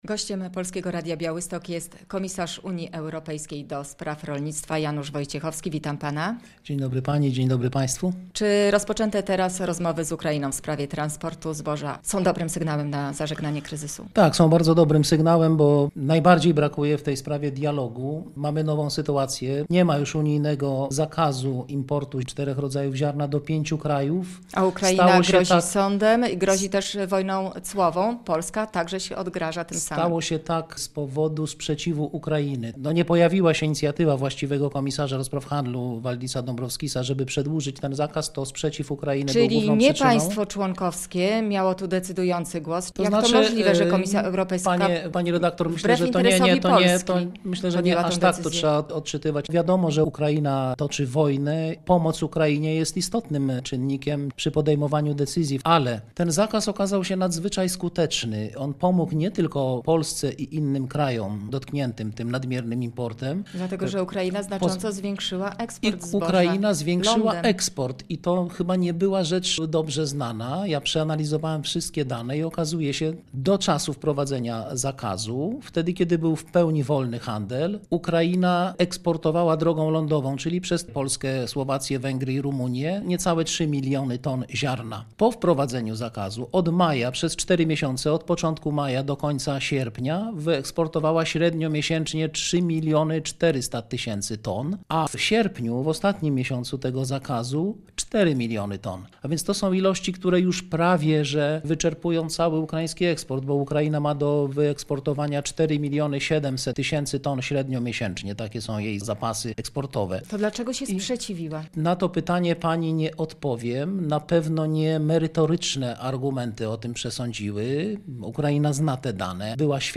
komisarz Unii Europejskiej ds. rolnictwa
studio